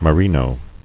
[me·ri·no]